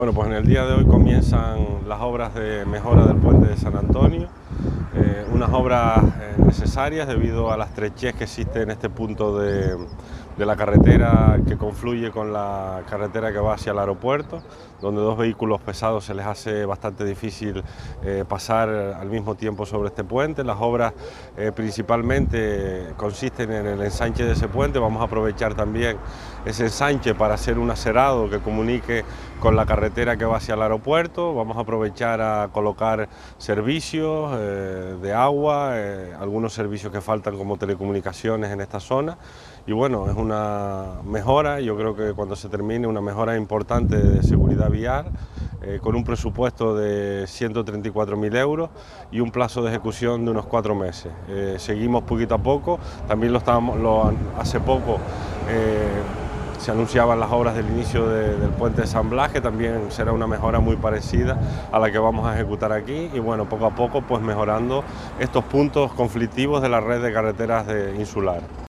Declaraciones Borja Perdomo audio.mp3